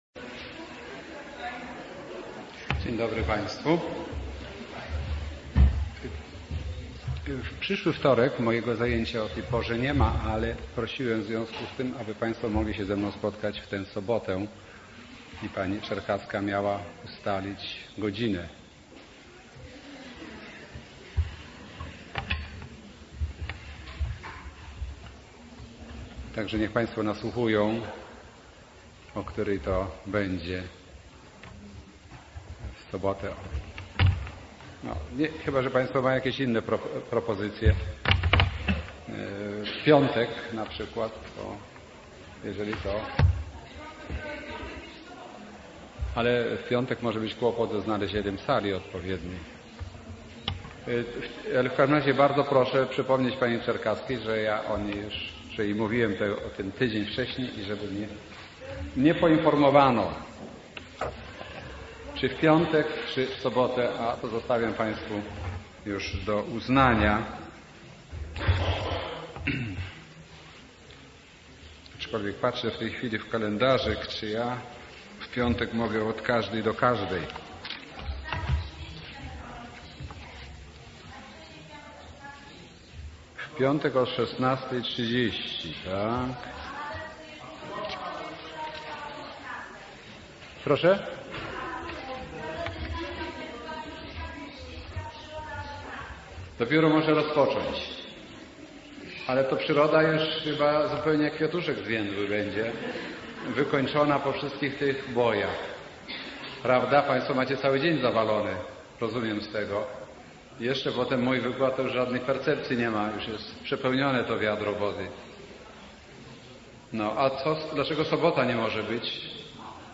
Wykłady Audio